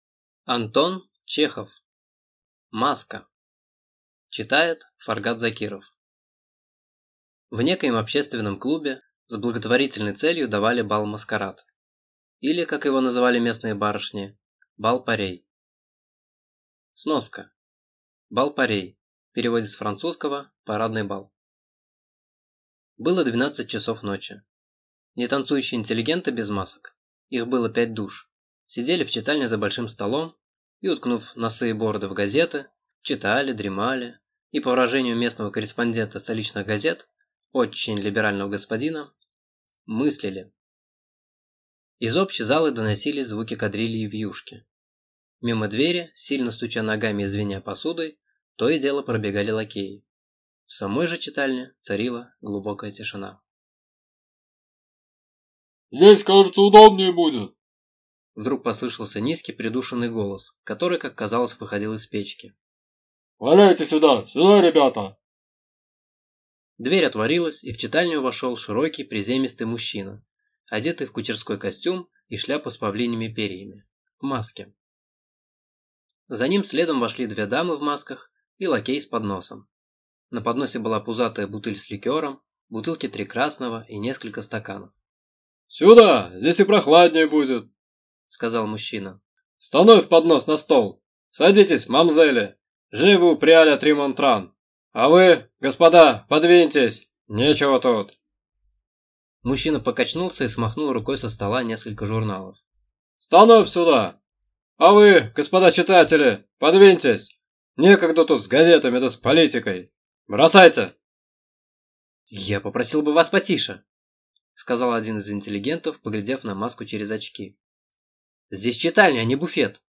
Аудиокнига Маска | Библиотека аудиокниг